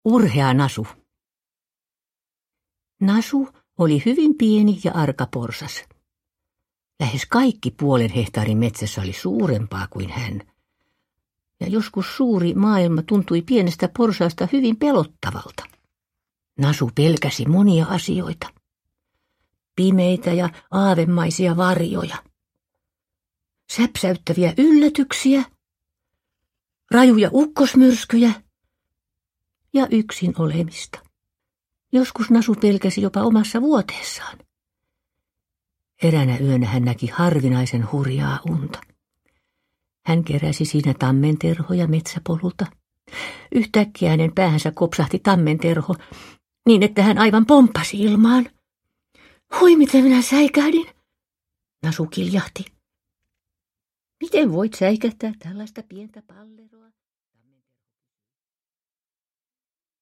Nalle Puh. Urhea Nasu – Ljudbok – Laddas ner
Uppläsare: Seela Sella